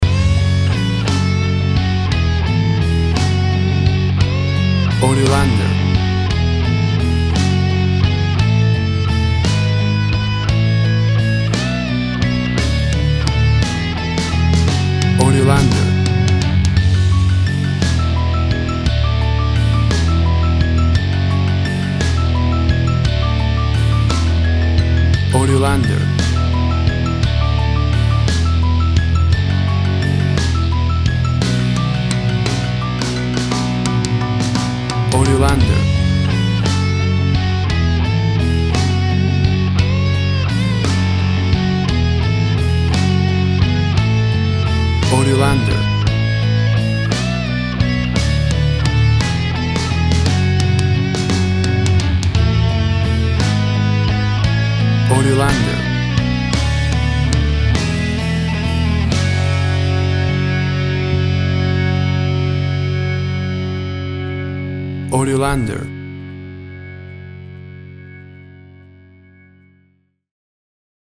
A big and powerful rocking version
WAV Sample Rate 16-Bit Stereo, 44.1 kHz
Tempo (BPM) 175